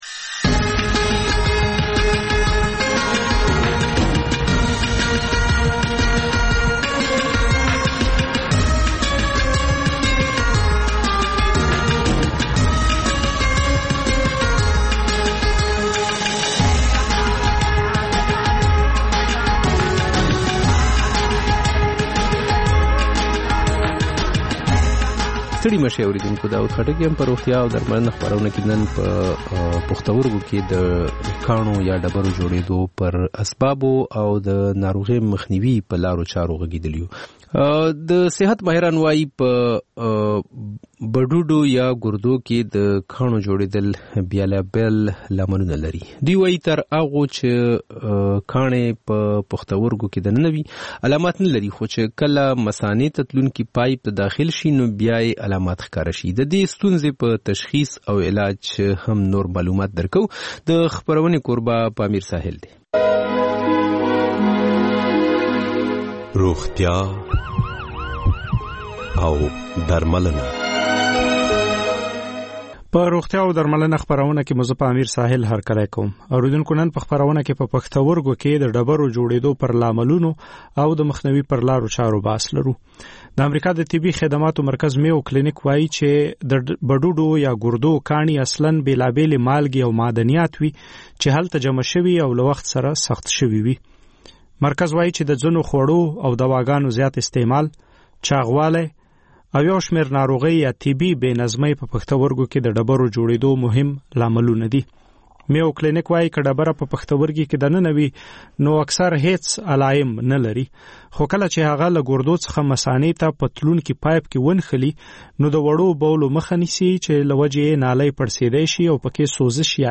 د مشال راډیو ماښامنۍ خپرونه. د خپرونې پیل له خبرونو کېږي، بیا ورپسې رپورټونه خپرېږي. ورسره یوه اوونیزه خپرونه درخپروو.